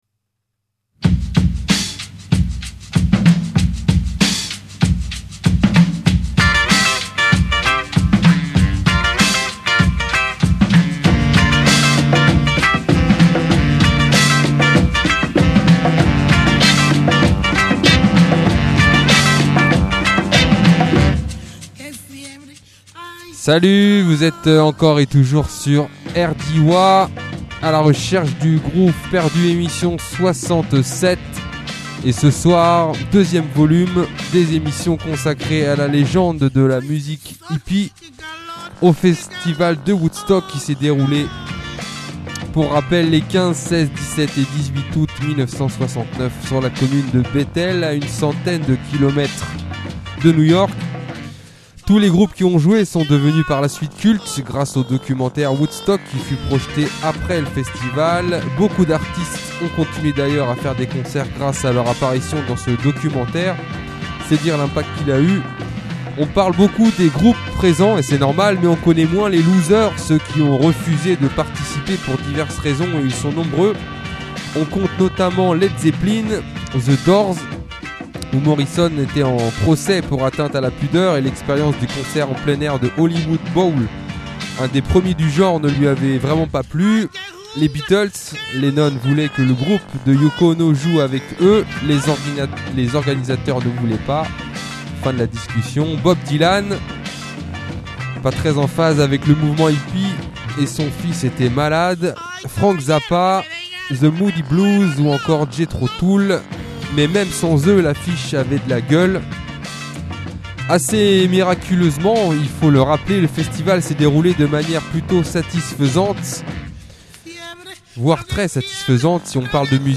blues , concert , festival , funk , rock